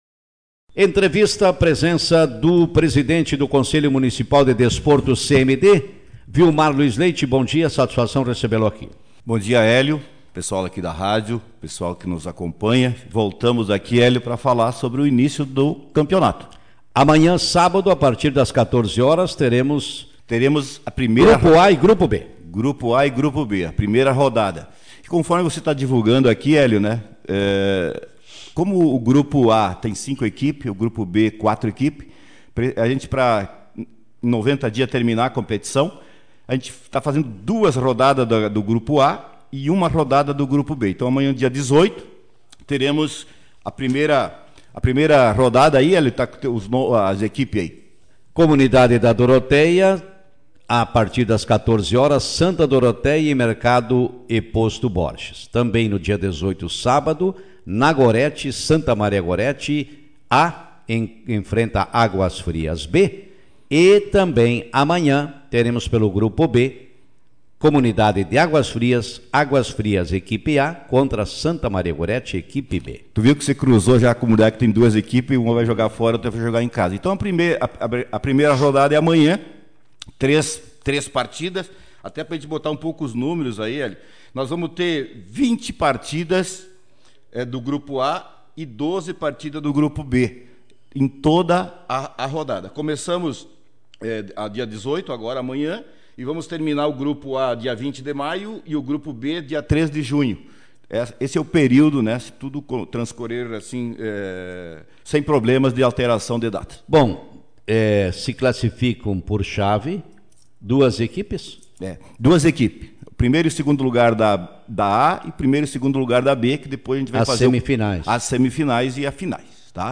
Nesta manhã, no programa Café com Notícias, membros do Conselho Municipal de Desporto (CMD), de Iraí, detalharam como funcionará o Campeonato Municipal de Bocha que iniciará neste sábado.